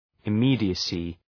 Shkrimi fonetik {ı’mi:dıəsı}